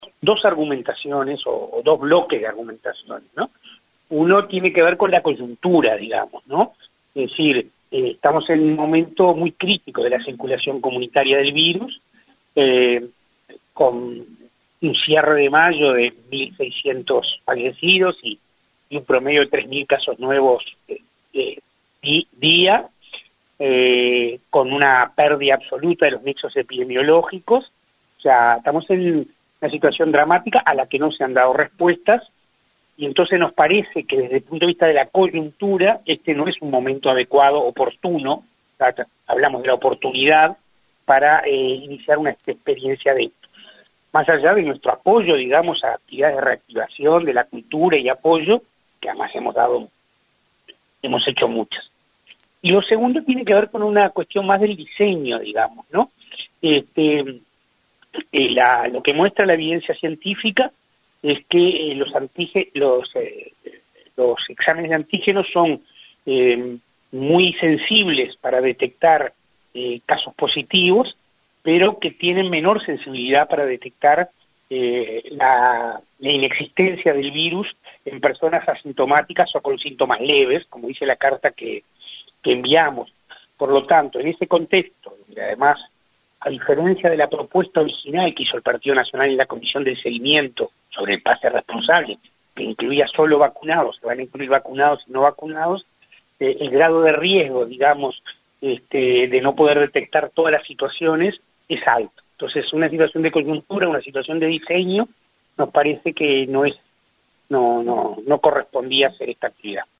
Olesker, en diálogo con 970 Noticias, señaló que se trata de dos argumentos principales: el momento crítico de circulación del Covid-19 y «cuestiones de diseño» de la herramienta.
Escuche a Olesker aquí